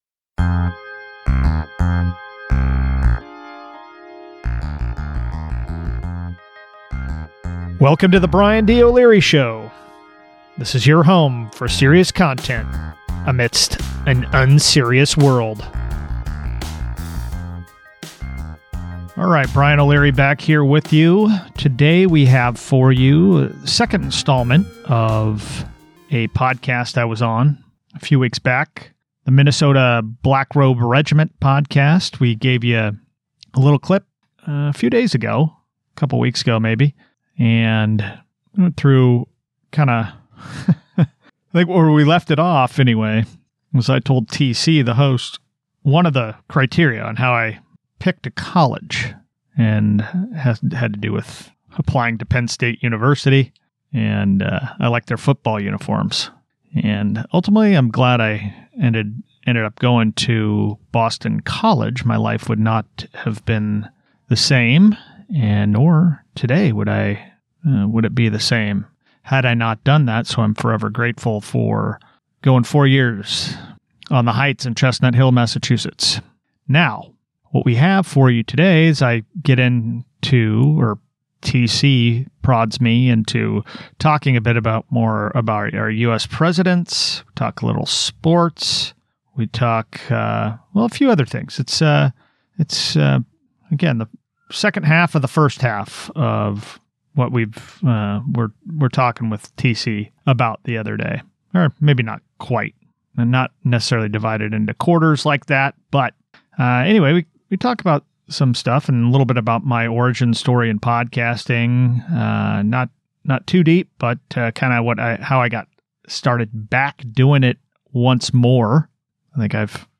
We’ll drop the rest of the interview in pieces over the next few weeks.